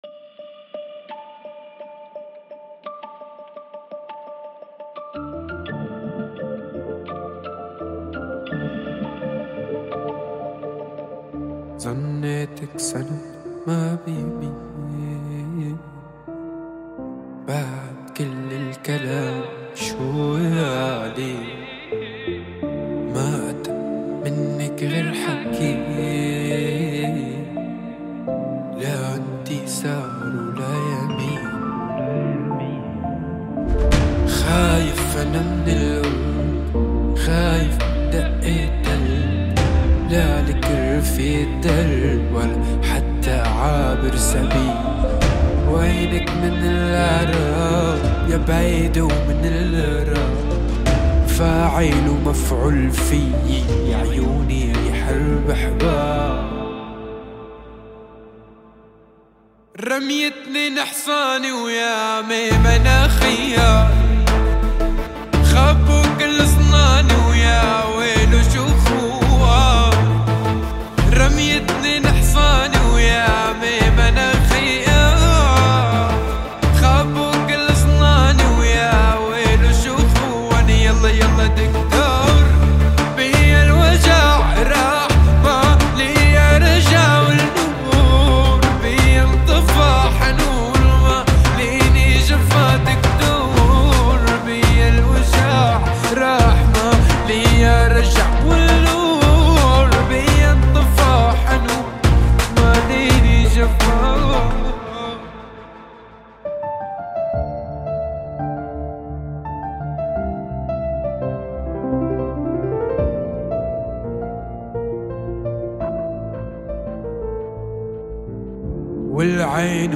مزيجاً ساحراً من الكلمات العميقة واللحن المؤثر.
رحلة موسيقية درامية